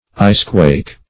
Search Result for " icequake" : The Collaborative International Dictionary of English v.0.48: Icequake \Ice"quake`\ ([imac]s"kw[=a]k`), n. The crash or concussion attending the breaking up of masses of ice, -- often due to contraction from extreme cold.